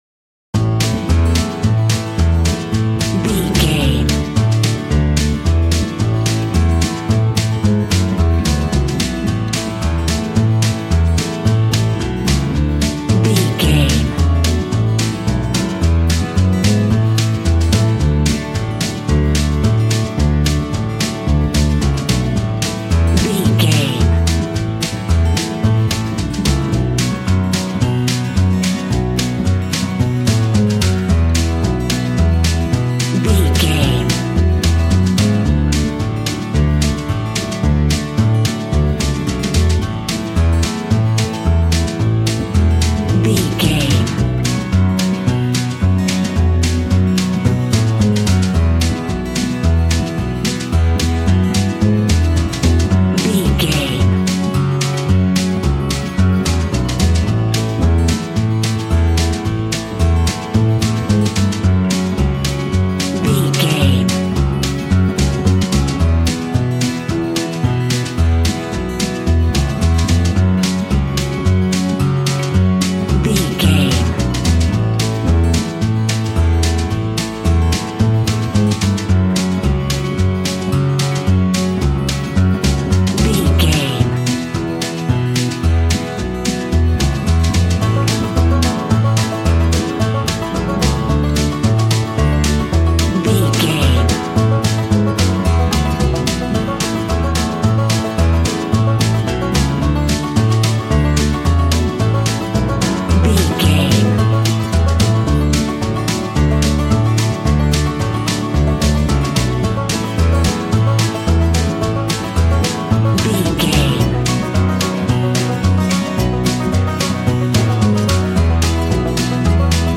Ionian/Major
Fast
fun
bouncy
double bass
drums
acoustic guitar